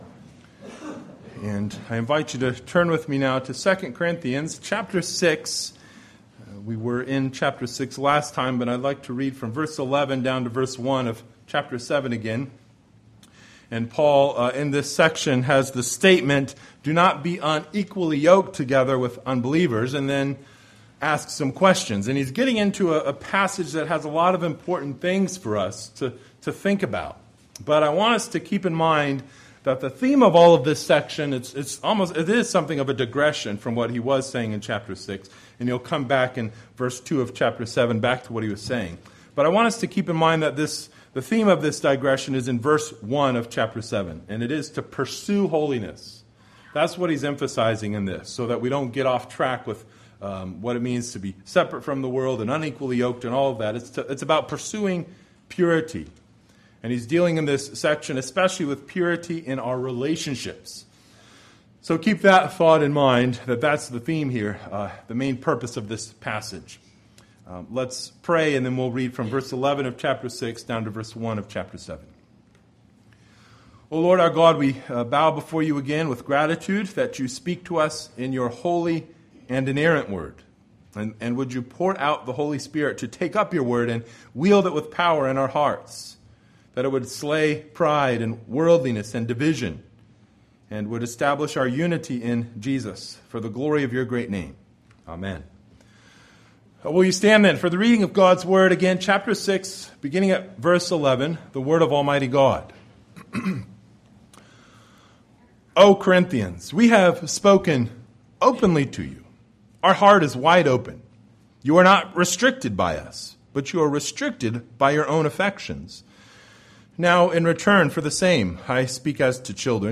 Passage: II Corinthians 6:11 - 7:1 Service Type: Sunday Evening